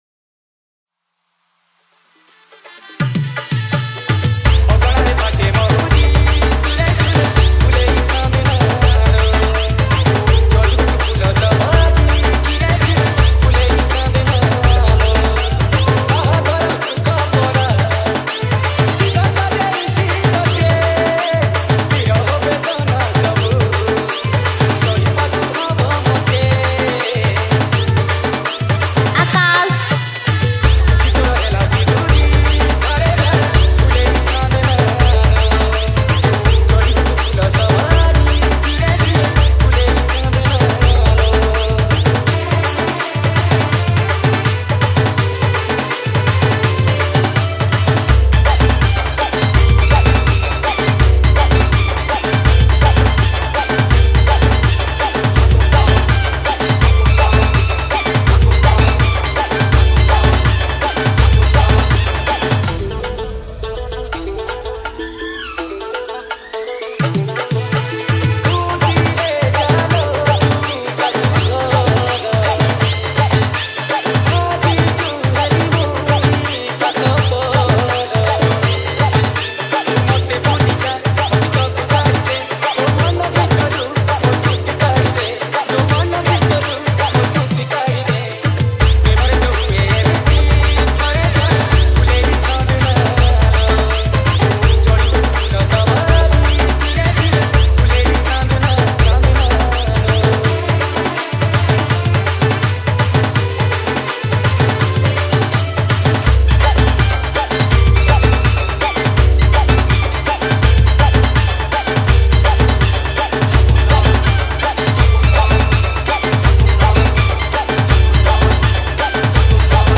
Category:  Old Sambalpuri Dj Song